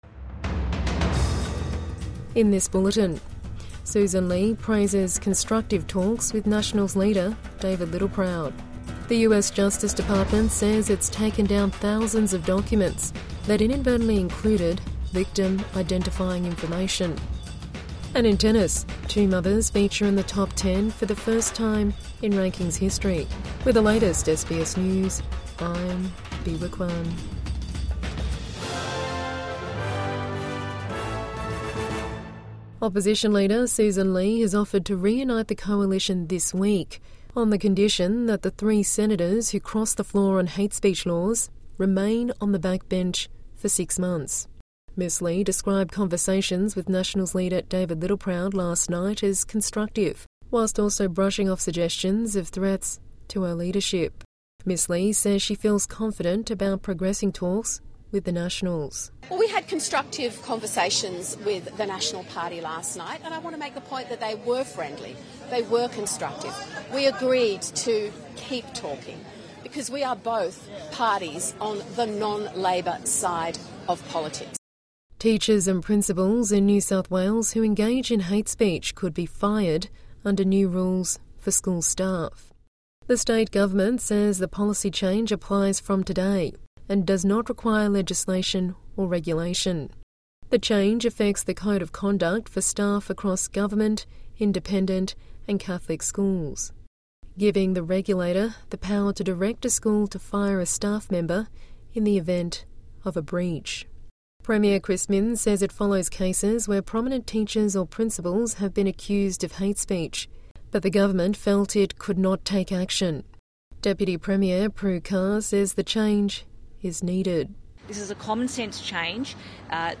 Listen to Australian and world news and follow trending topics with SBS News Podcasts.